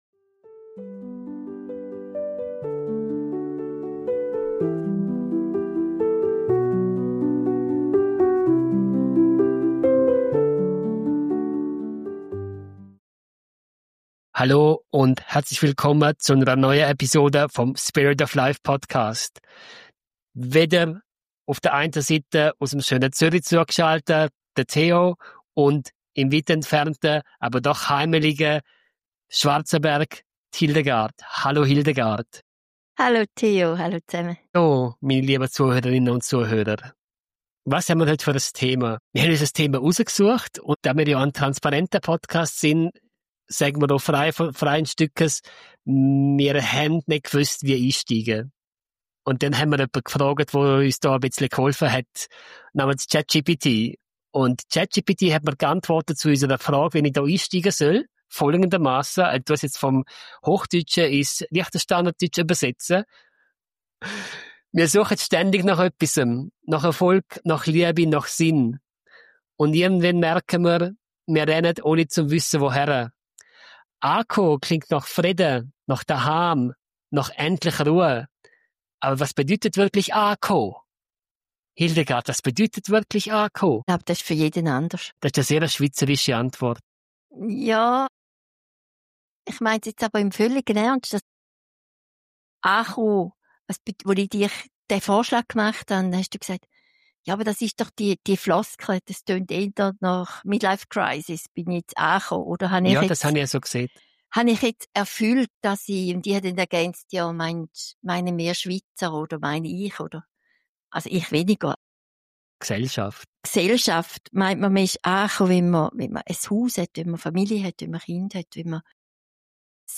Ein ehrliches, warmes Gespräch über Bewegung, Veränderung und innere Ruhe – und darüber, warum Ankommen kein Endpunkt ist, sondern ein Weg.